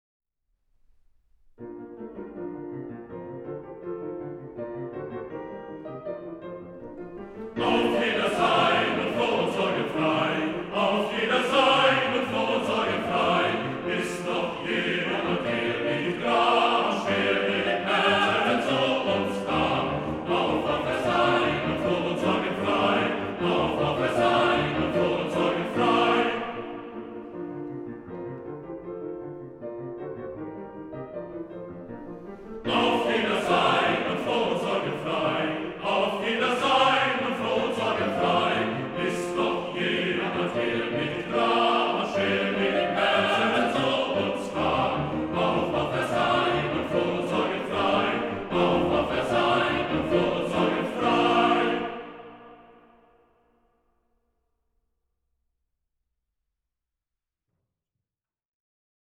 Gesänge für Männerstimmen und Klavier
Klavier